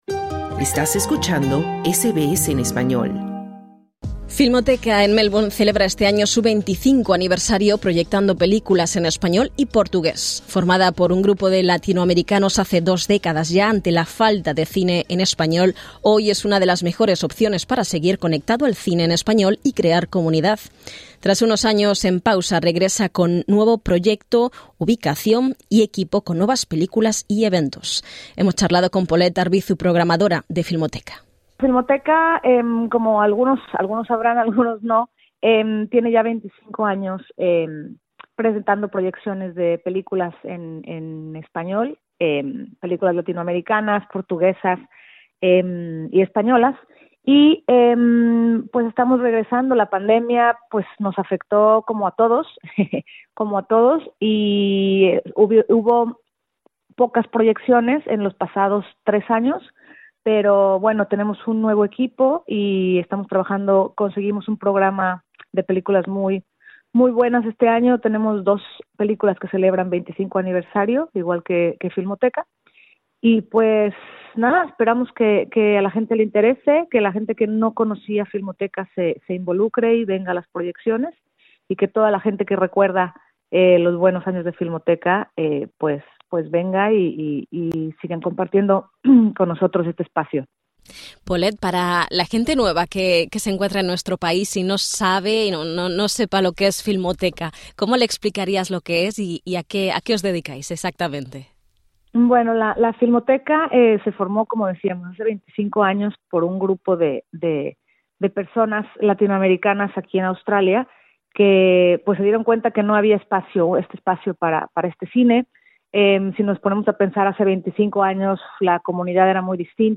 Escucha la entrevista en el podcast que se encuentra al inicio de la página.